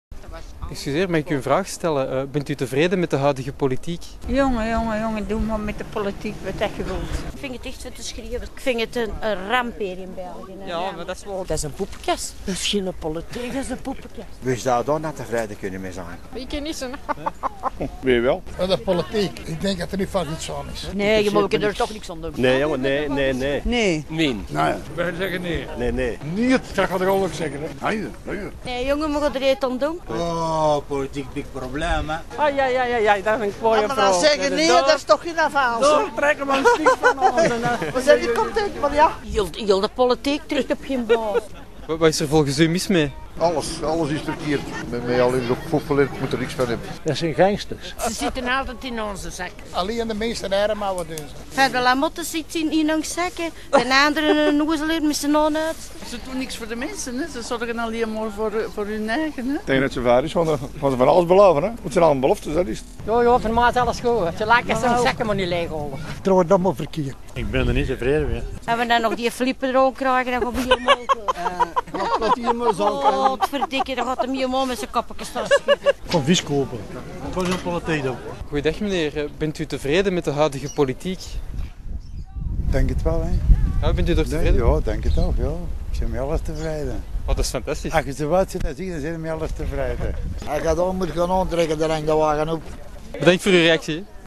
marktcompilatie1.wma